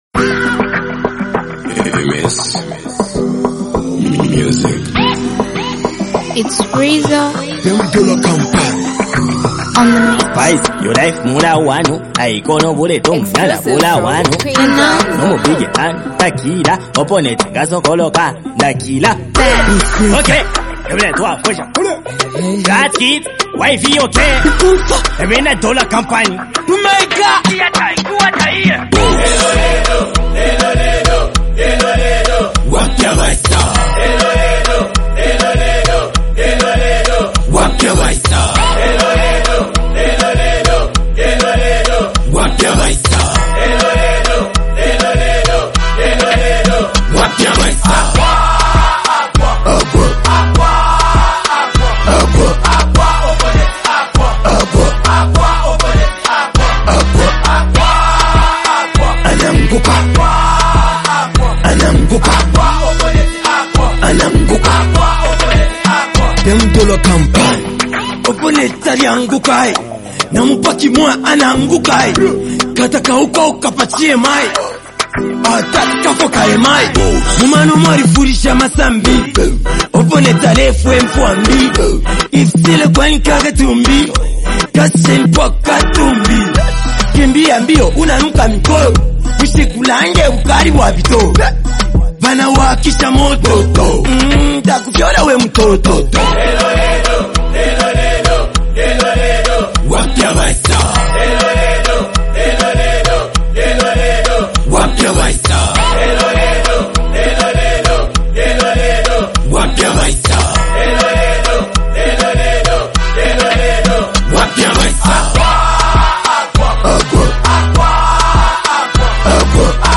high-energy street anthem
raw and energetic flow
smooth yet assertive style
The chorus is catchy and easy to sing along to